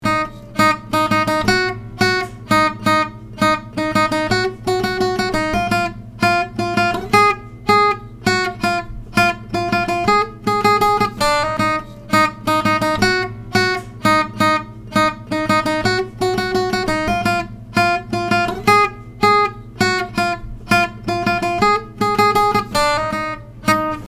Play on the [B] string